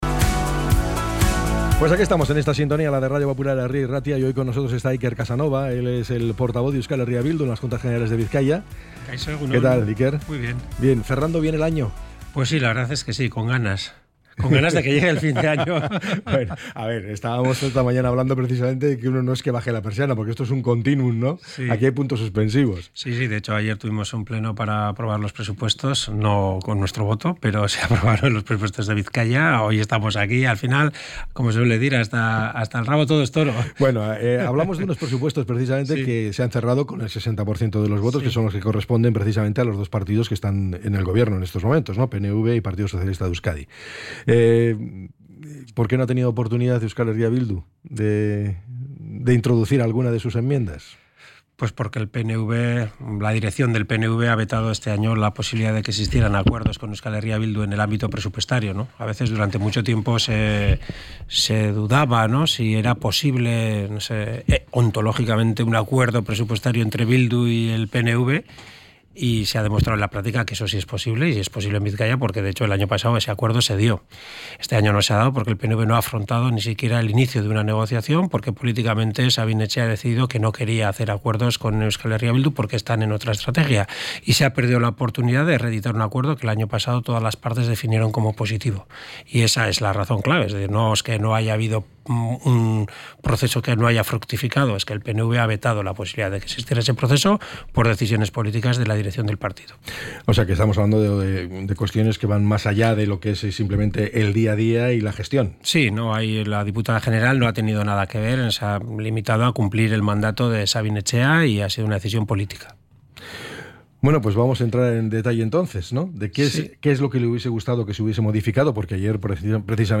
ENTREV.-IKER-CASANOVA.mp3